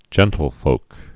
(jĕntl-fōk) also gen·tle·folks (-fōks)